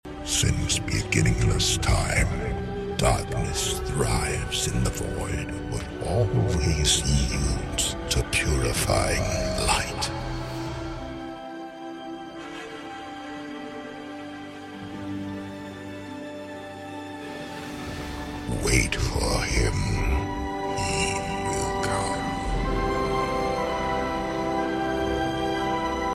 Lion Turtle English voice over! sound effects free download